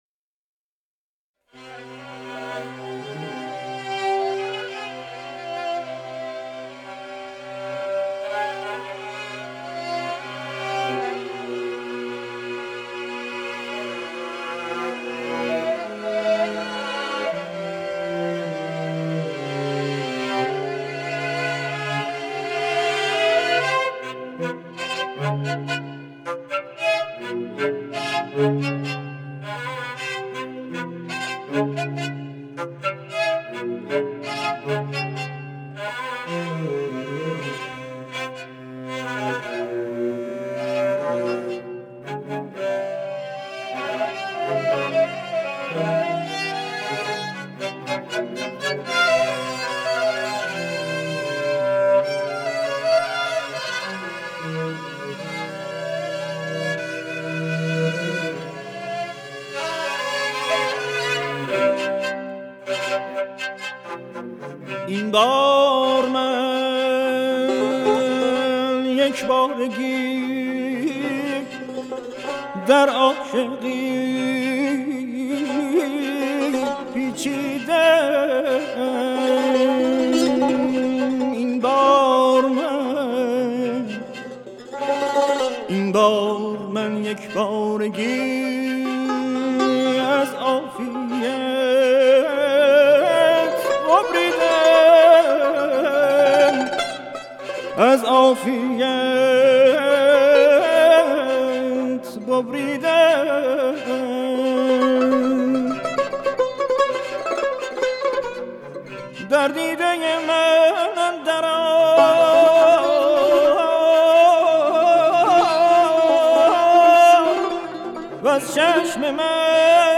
• سنتی ایرانی
دسته : سنتی ایرانی